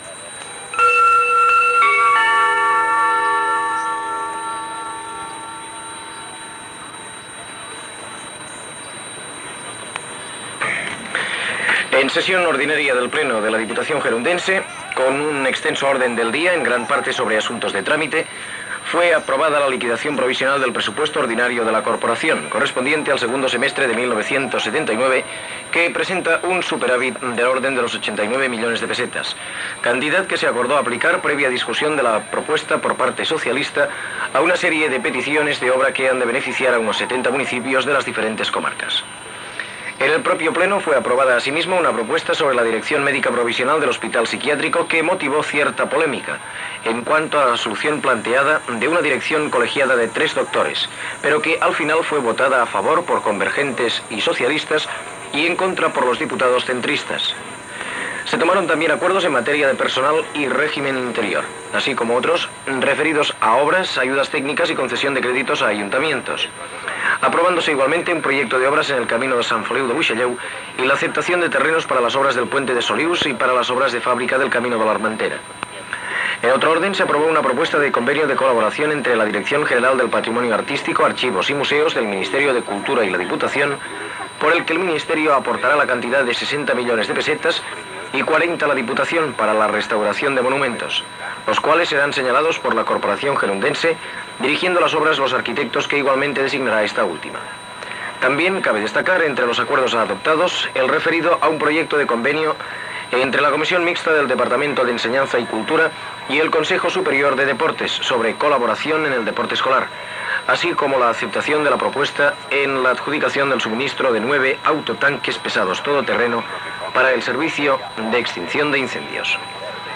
Ple ordinari de l'Ajuntament de Girona, aprovats els comptes de 1979, Hospital Psiquiàtric, nomenament del parlamentari López de Lerma a la comissió de RTVE, tall del suministrament d'Hidroelèctrica, etc. Entrevista sobre la coalició electoral Bloc d'Esquerra d'Alliberament Nacional (BEAN) Unitat Popular. Editorial sobre les eleccions al Parlament de Catalunya, careta de sortida del programa, publicitat